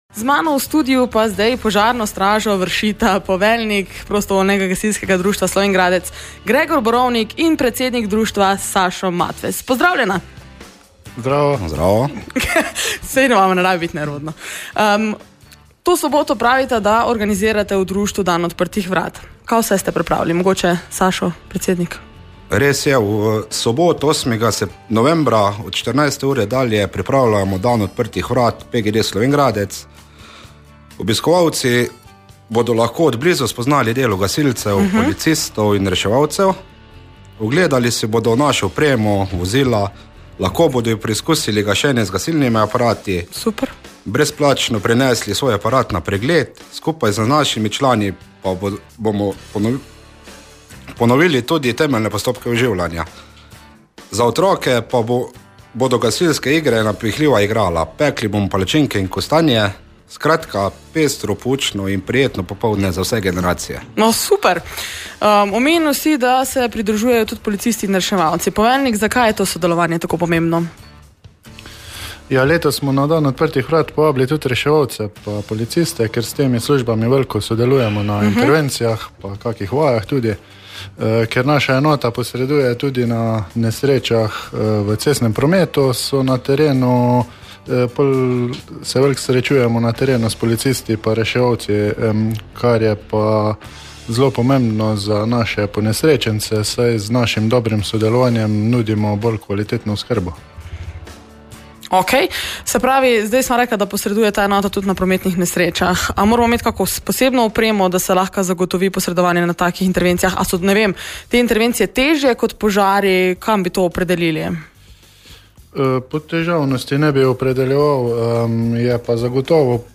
V pogovoru sta poudarila, da bodo med drugim nudili tudi brezplačne preglede gasilnih aparatov za vse občane in mimoidoče.